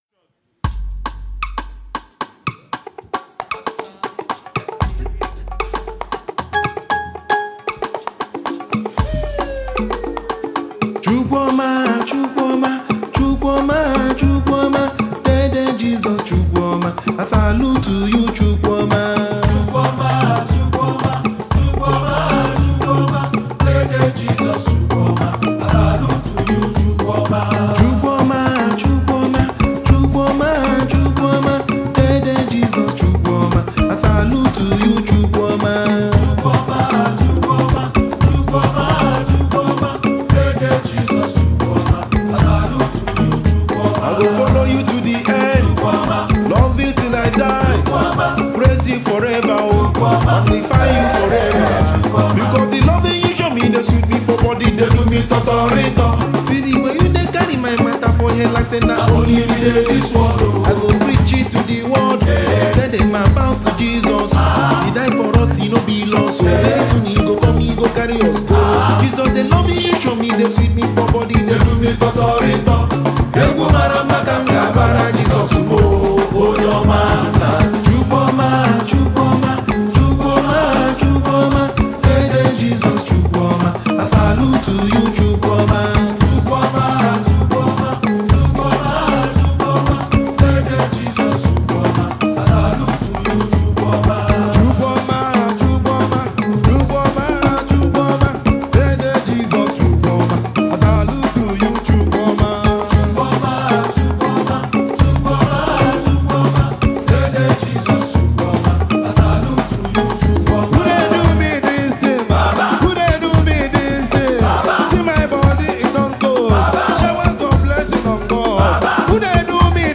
Nigerian gospel music
songwriter and saxophonist.
music comes heavily blended in traditional African rhythms